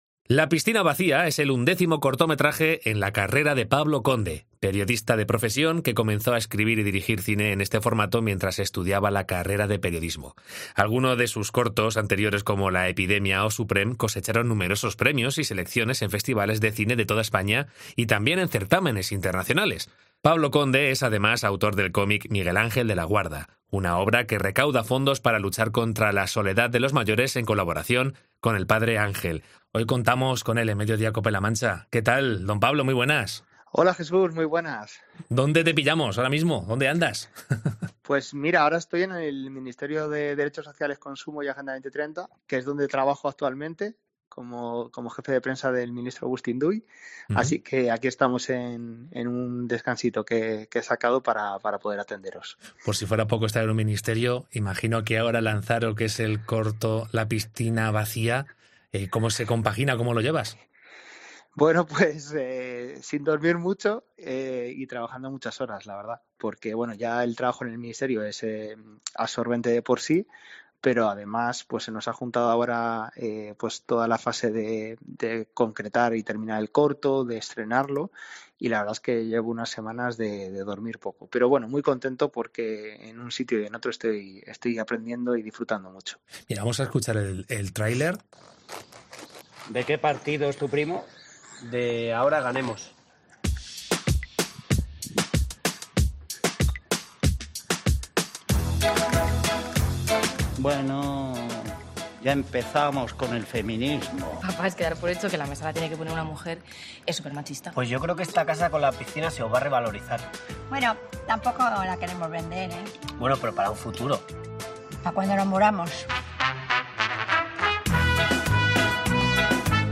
En Cope La Mancha Entrevista